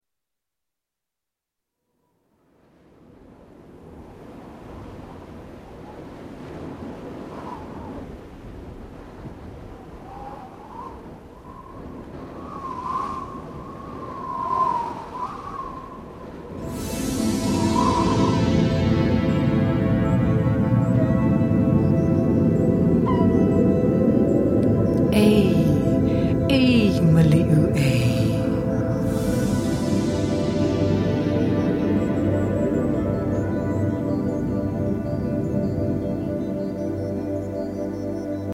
• Genre: Instructional